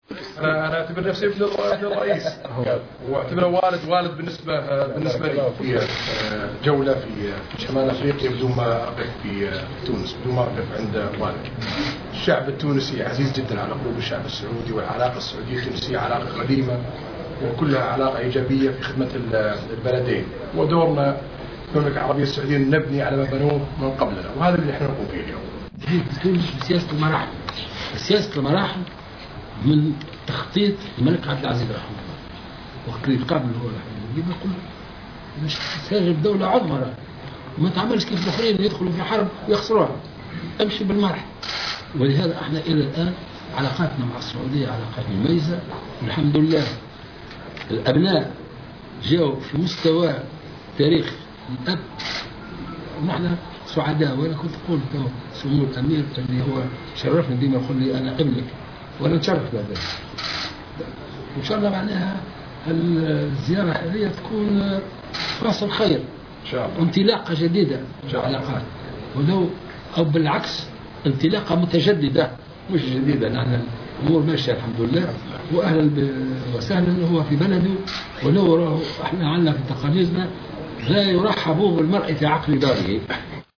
وفي تصريح للقناة الوطنية، أكد الأمير السعودي أنه يعتبر الرئيس التونسي الباجي قائد السبسي "بمثابة والده" مضيفا أن الشعب التونسي هو شعب عزيز جدا لدى السعوديين، ومنوها بمتانة العلاقة بين البلدين الشقيقين.